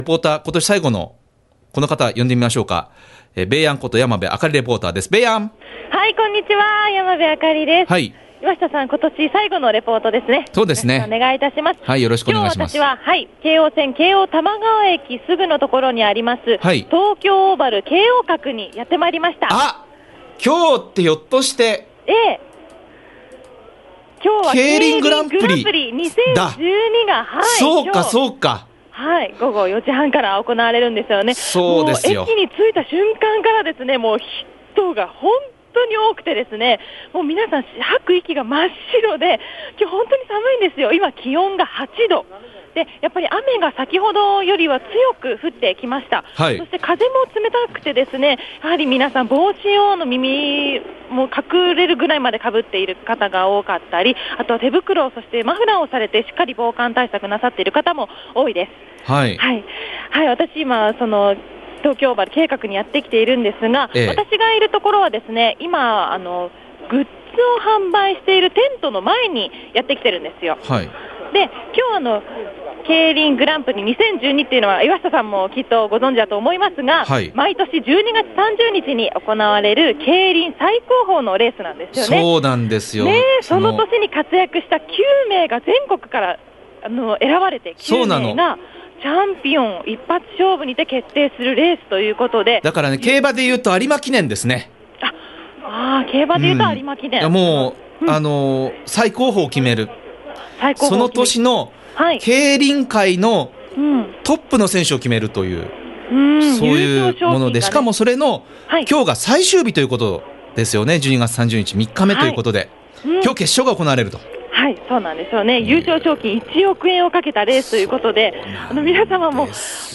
２０１２年最後のレポートは、京王線京王多摩川駅すぐの東京オーヴァル京王閣です。
今日はKEIRINグランプリ２０１２！